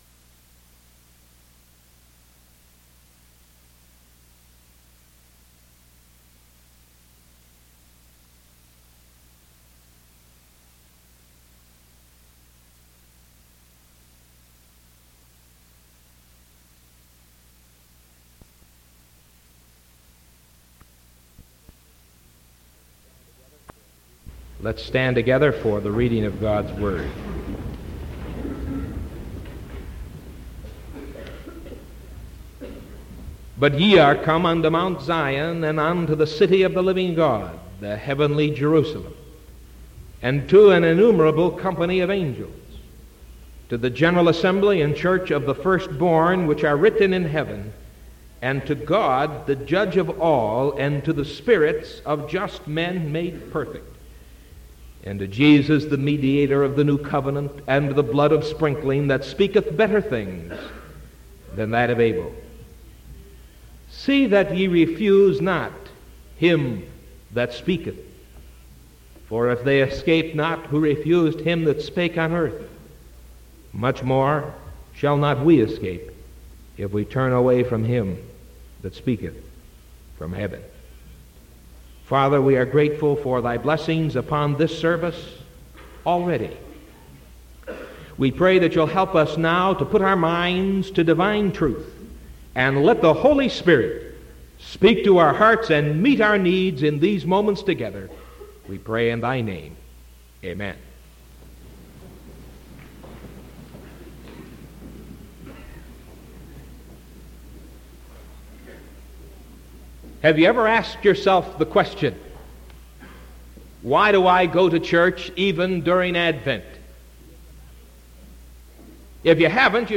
Sermon from December 15th 1974 AM